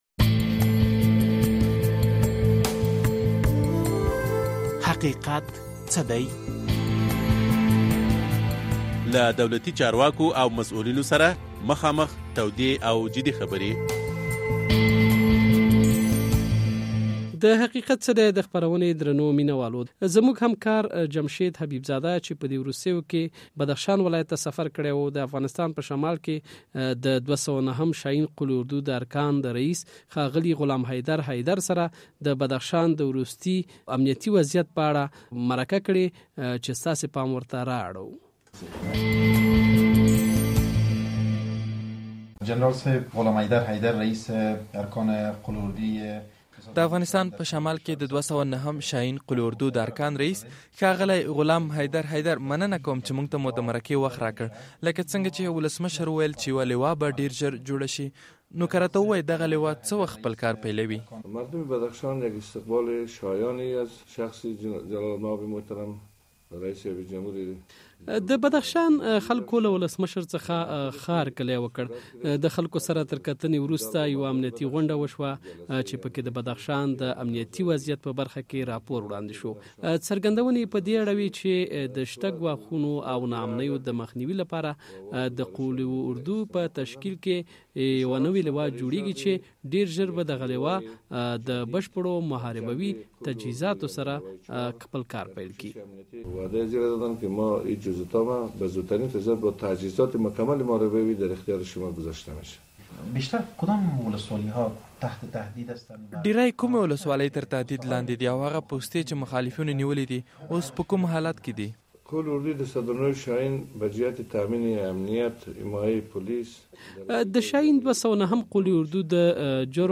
مرکه کړې او په ځانګړي ډول یې په بدخشان ولایت کې د مخالفینو د ورستۍ حملې د جزیاتو په اړه ورڅخه پوښتلي دي.